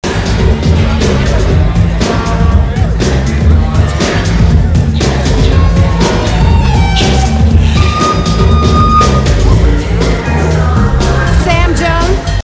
Comment: rock